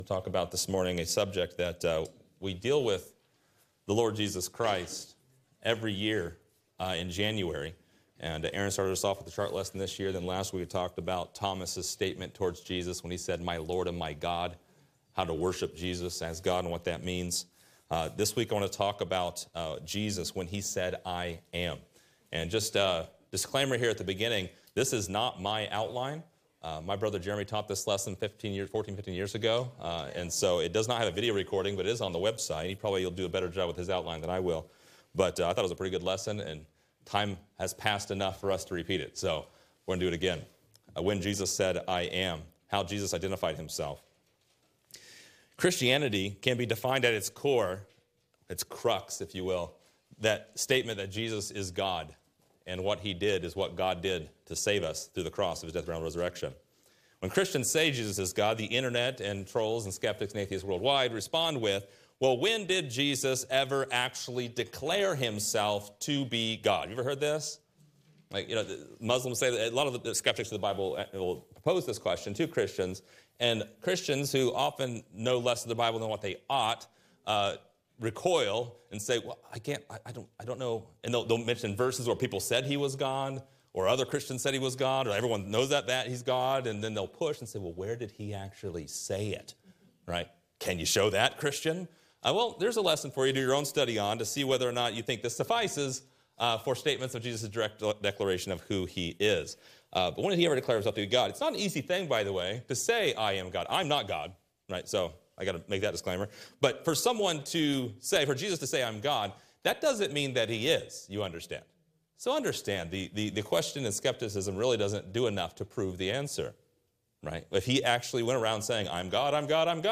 Most Christians maintain the claim that Jesus is God, but skeptics often ask when Jesus claimed to be God. In this lesson, we study a list of instances in the Bible where Jesus claimed to be God.